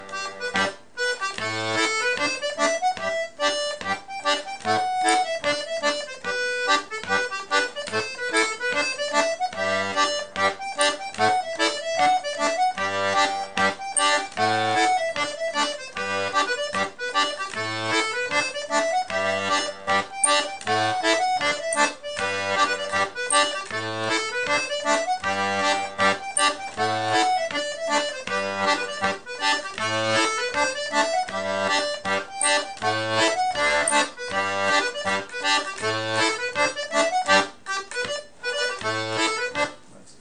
l'atelier d'accordéon diatonique
Pour l'apprendre utiliser les automatismes de la gamme de sol majeur (sol, la, si, do ré, mi, fa#) en poussé ou en tiré...